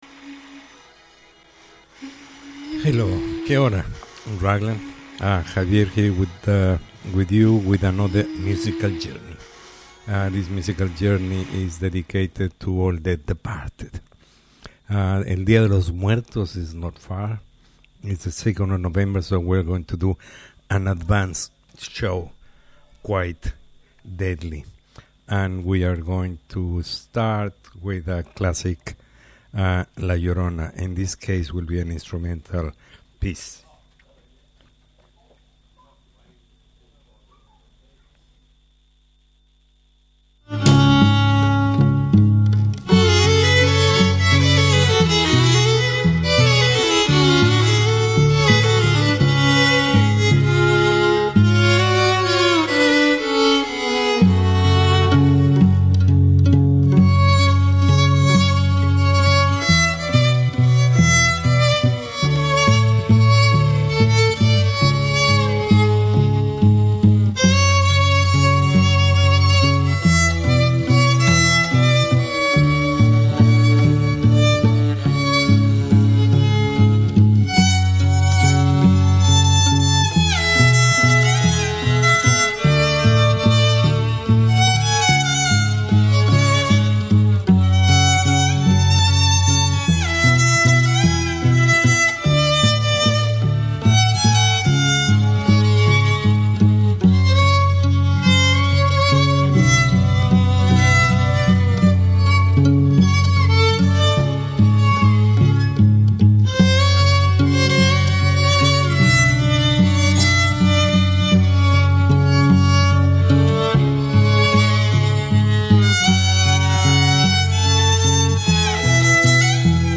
Songs dedicated to the departed.